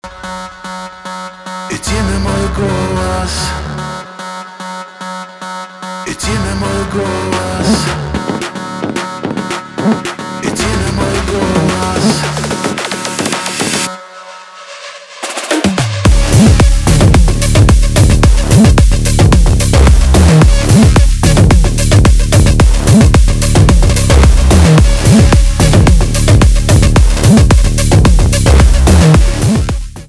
электроника
битовые , басы , качающие